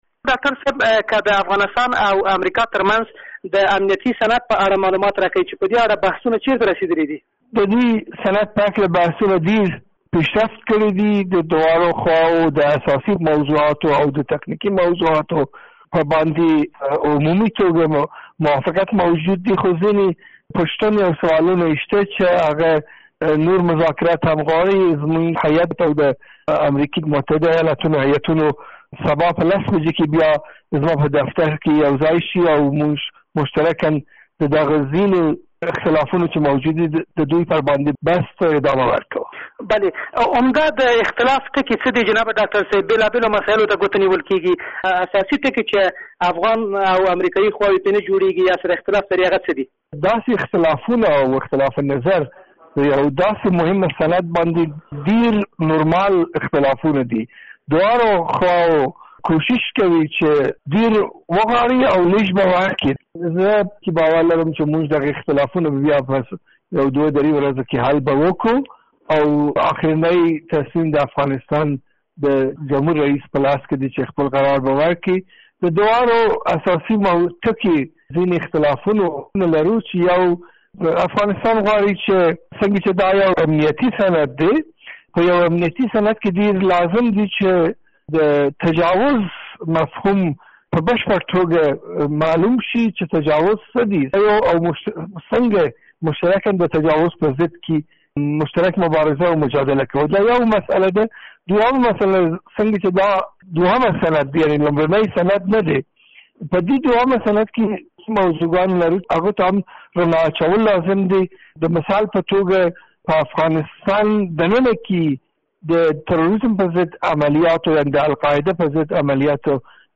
له سپانتا سره مرکه